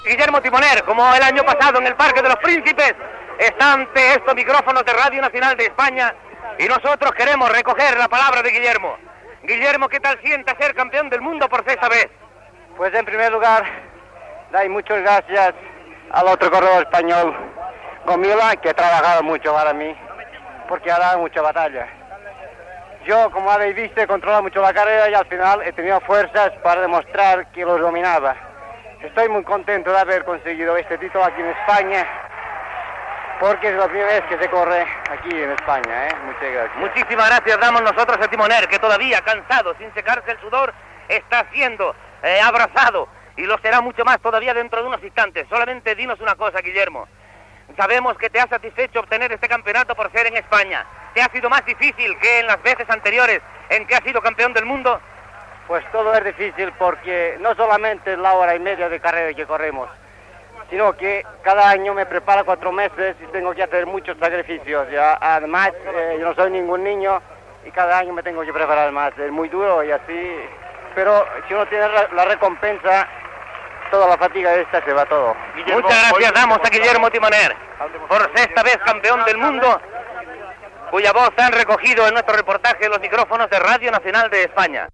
Entrevista a Guillermo Timoner que s'ha proclamat per sisena vegada campió del món de mig fons de ciclisme en pista darrere motocicleta, a Donòstia
Esportiu